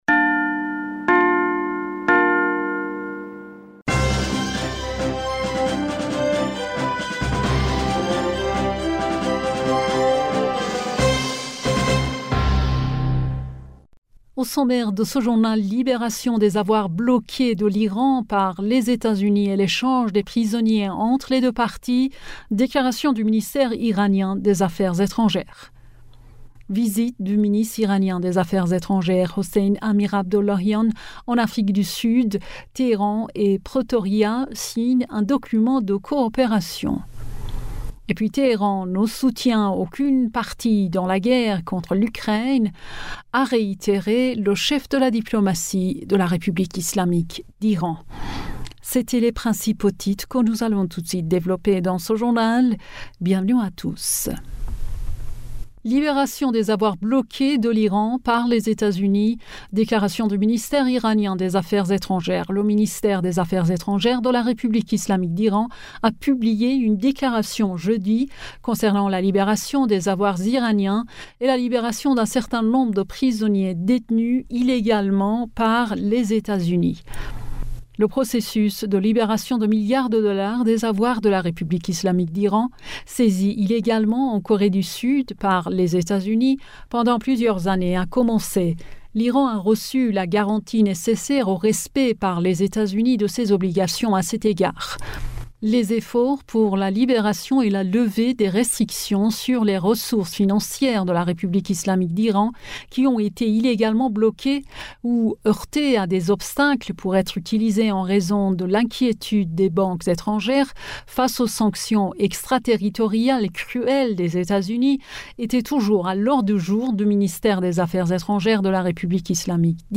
Bulletin d'information du 11 Aout 2023